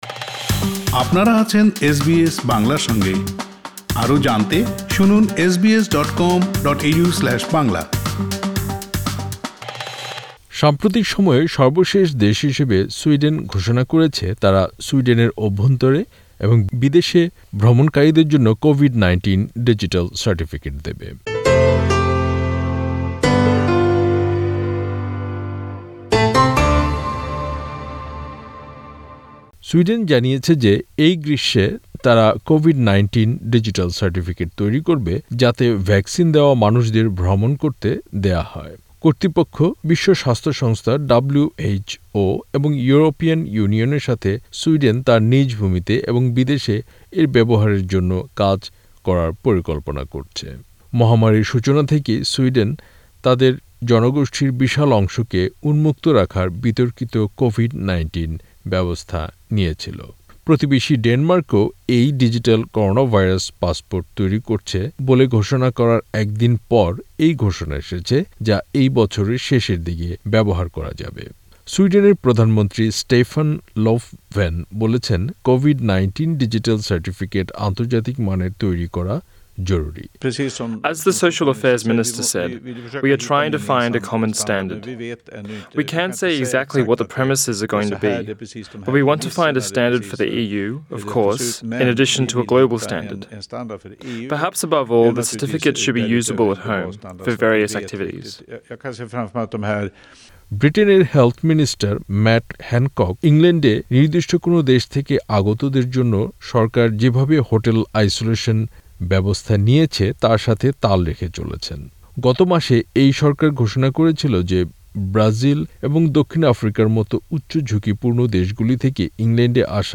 সাম্প্রতিক সময়ে সর্বশেষ দেশ হিসেবে সুইডেন ঘোষণা করেছে তারা সুইডেনের অভ্যন্তরে এবং বিদেশ ভ্রমণকারীদের জন্য কোভিড ১৯ ডিজিটাল সার্টিফিকেট দেবে। কোভিড ১৯ ডিজিটাল সার্টিফিকেট এবং সারা বিশ্বে ভ্যাকসিনেশন এবং ভাইরাস সম্পর্কিত কিছু সাম্প্রতিক খবর নিয়ে একটি প্রতিবেদন।